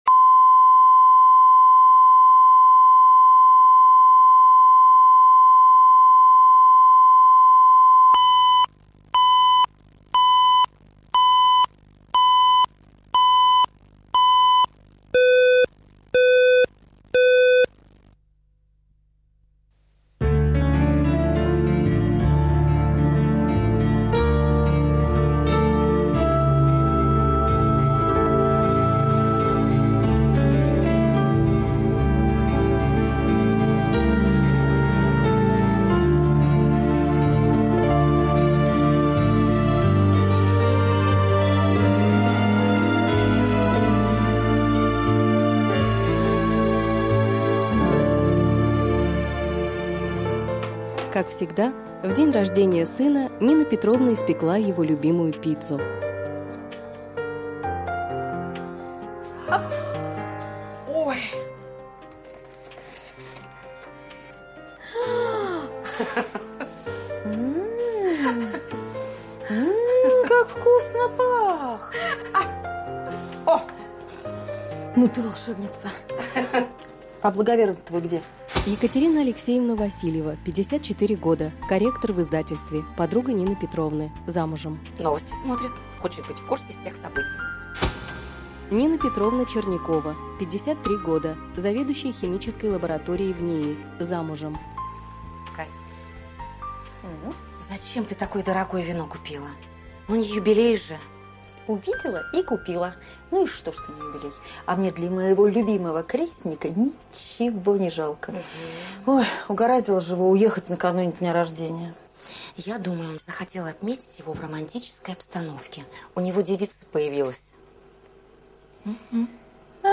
Аудиокнига Надо жить